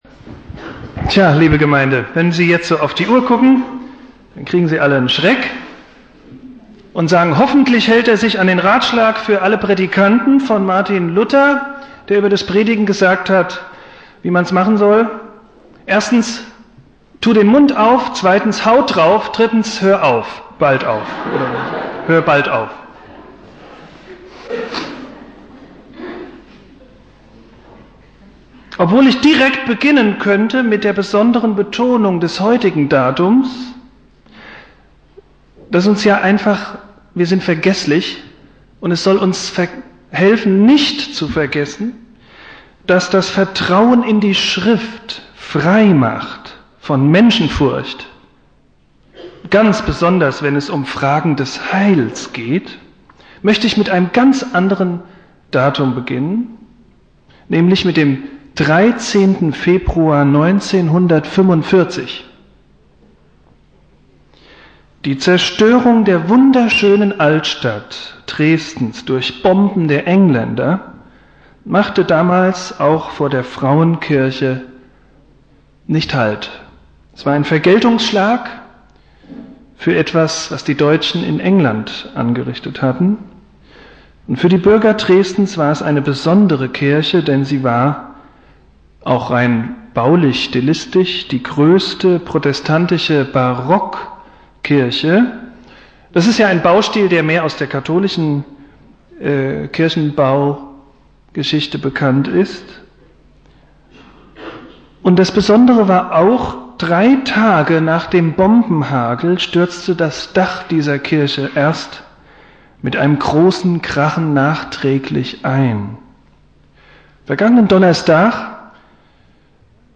Predigt
Reformationstag